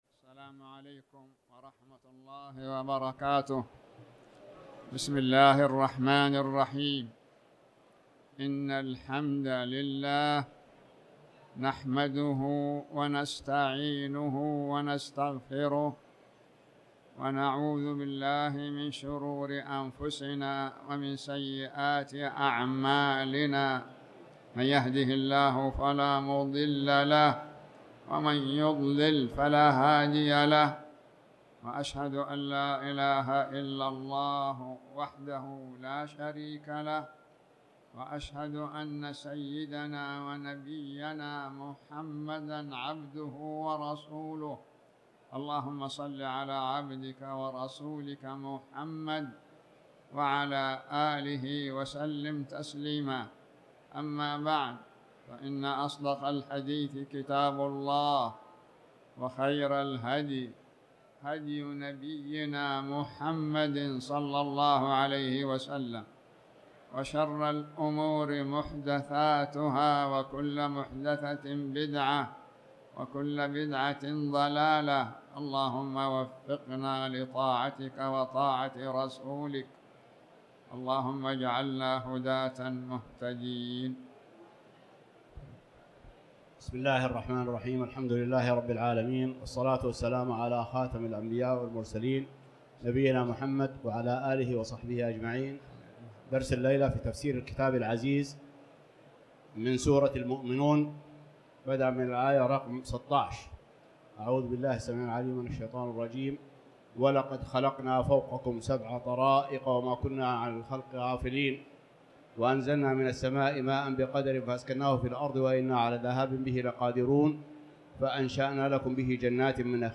تاريخ النشر ٢١ شعبان ١٤٣٩ هـ المكان: المسجد الحرام الشيخ